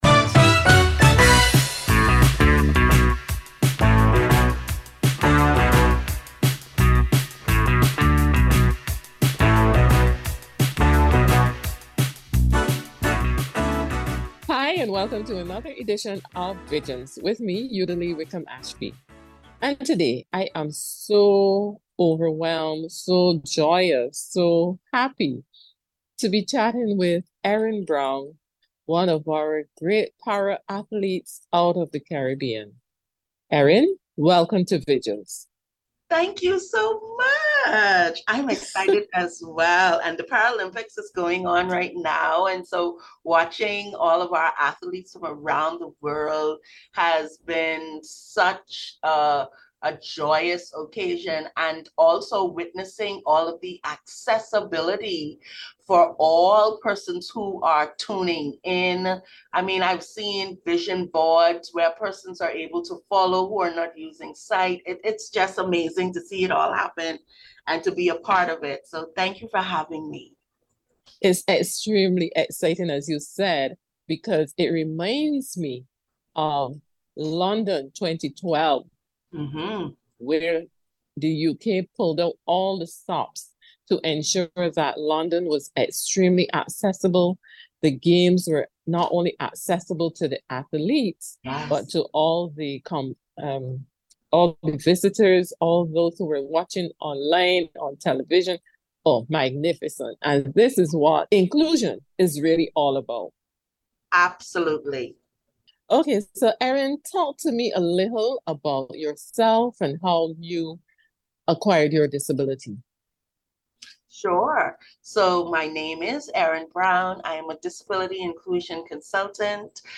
Inter view